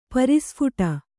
♪ pari sphuṭa